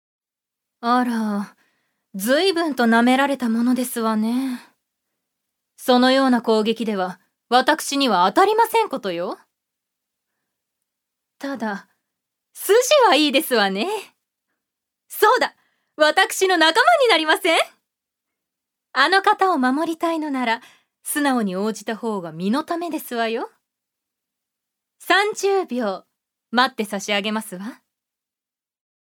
女性タレント
セリフ７